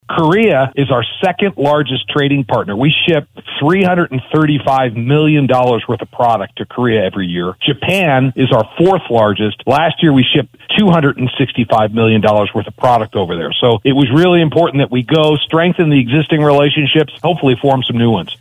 Today on Voices of Montana he said it was important to go, to strengthen current relationships and form new ones.
Gov. Greg Gianforte